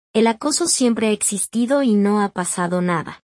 Creado por IA con CANVA